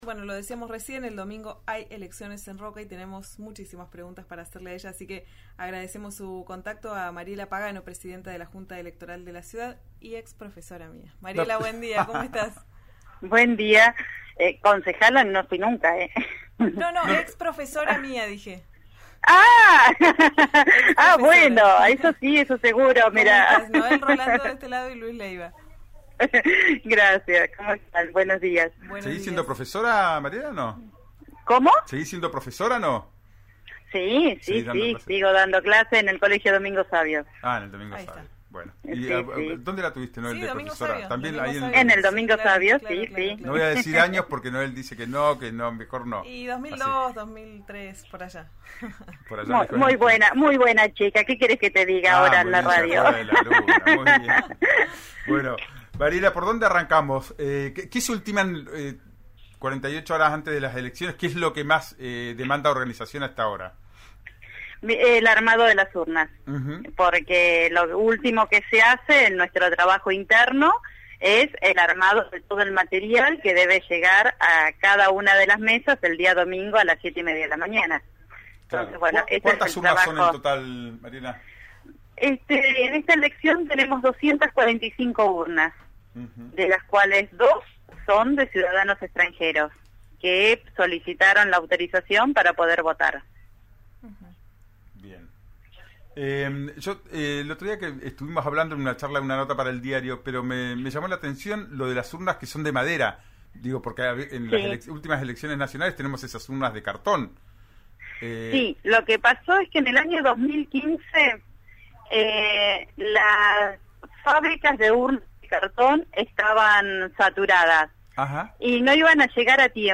RÍO NEGRO RADIO entrevistó a Mariela Pagano, presidenta de la Junta Electoral de Roca, que explicó el paso a paso para quienes no emitieron su voto.